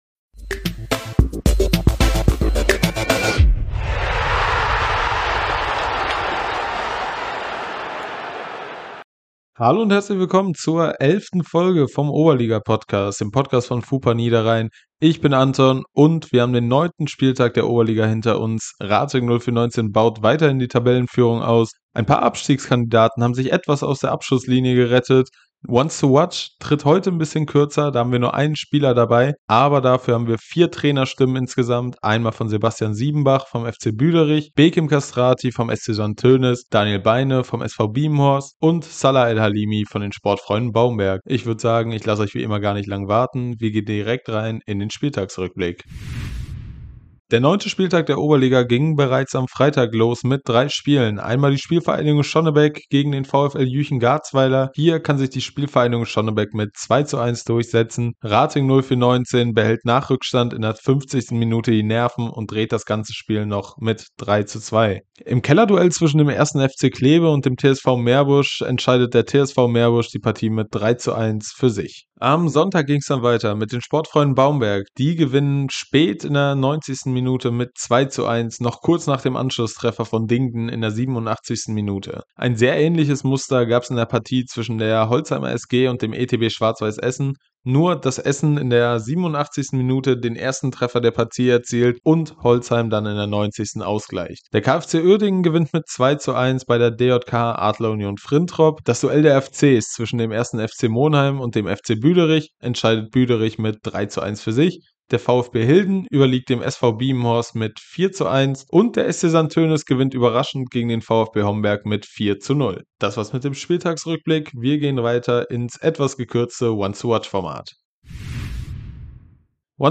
vier Trainer im Talk ~ FuPalaver - der Podcast von FuPa Niederrhein Podcast